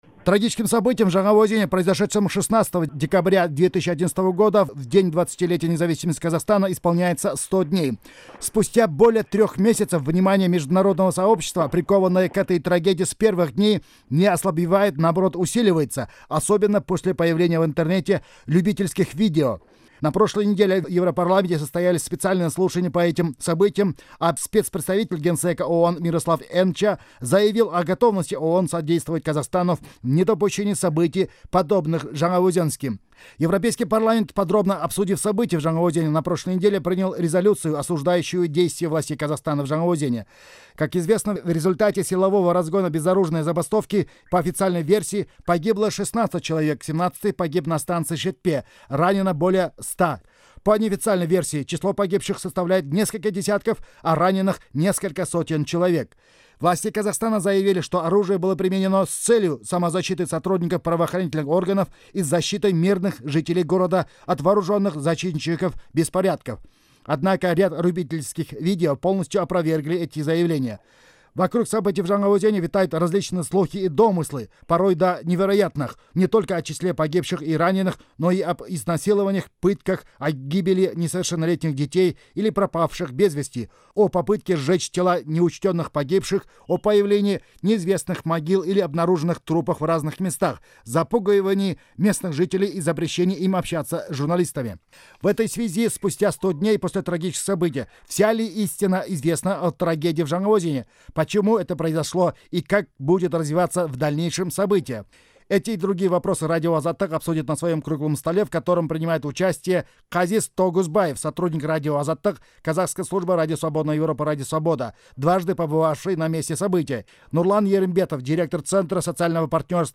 (Дөңгелек үстел). 23 наурыз 2012 жыл.